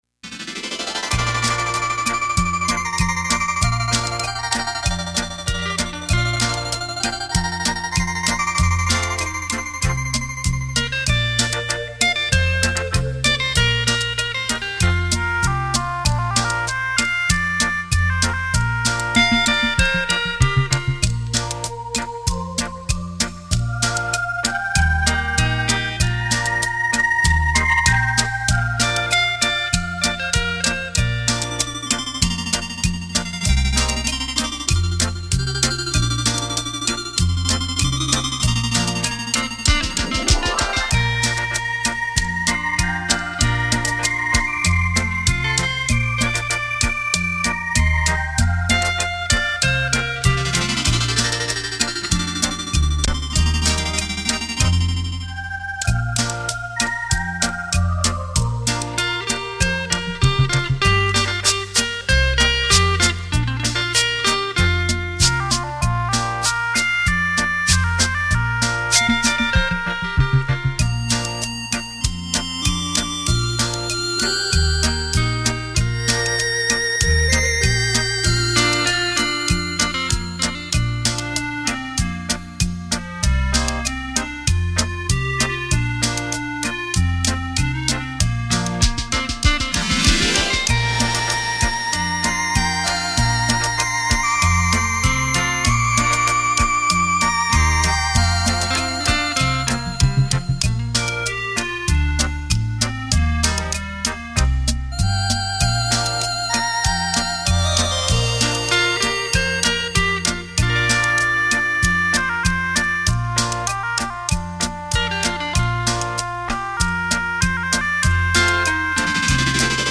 电子琴诠释经典 表现出另一种风情
经典的歌曲 全新的演绎 浪漫双电子琴带你回味往事